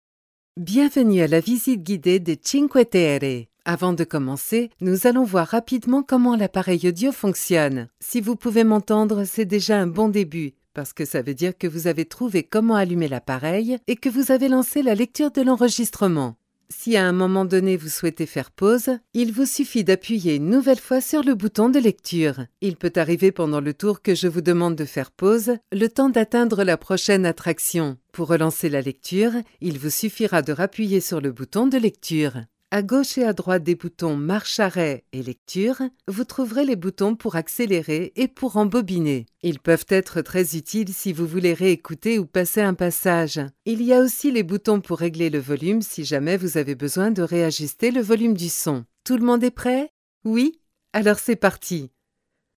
Natural
Sensual
Articulado